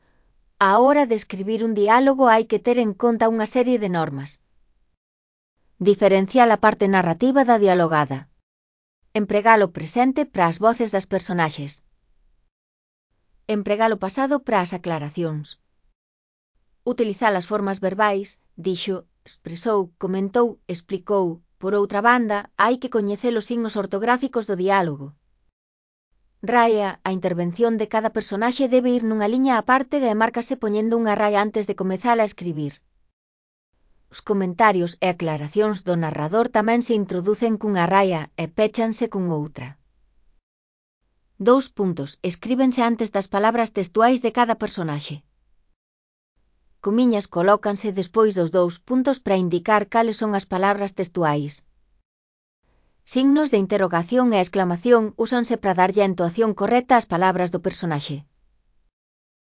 Elaboración propia (Proxecto cREAgal) con apoio de IA, voz sintética xerada co modelo Celtia.. Normas e signos ortográficos do diálogo. (CC BY-NC-SA)